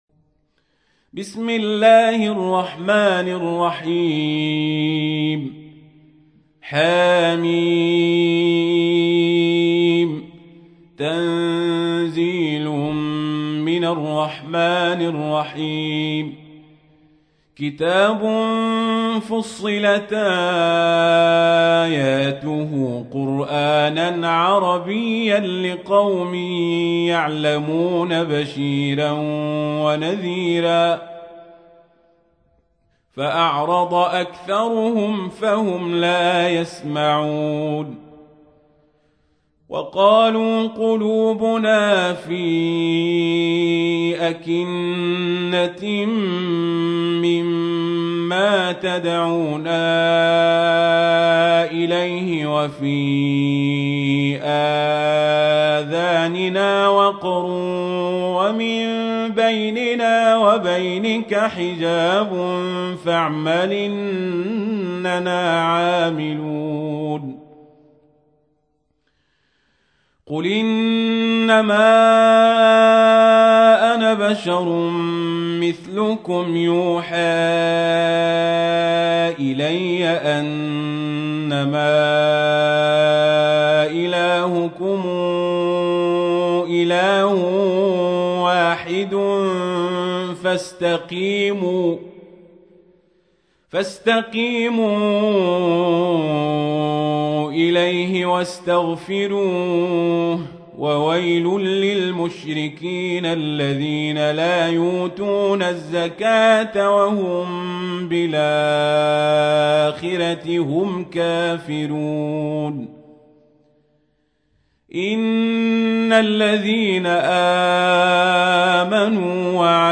41. سورة فصلت / القارئ